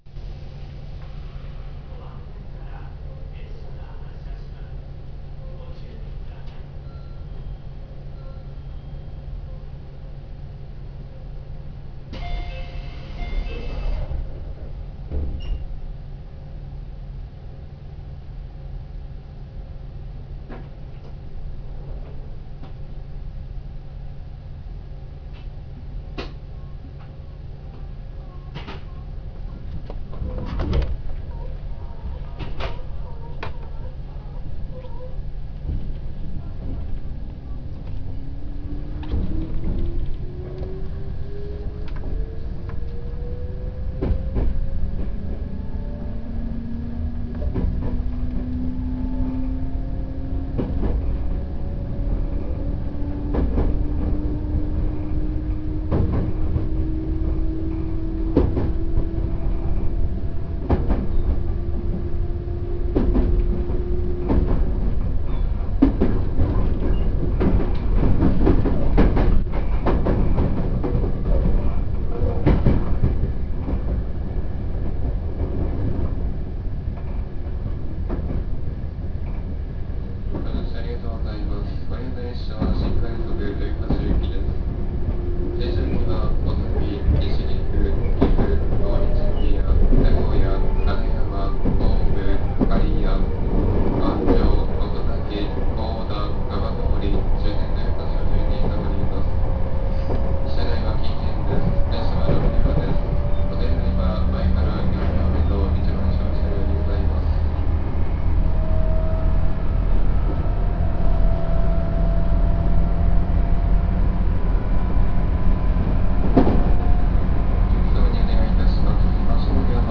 ・311系走行音
【東海道線】大垣→穂積（6分9秒：1.95MB）
界磁添加励磁制御で、走行音自体は211系と変わりません。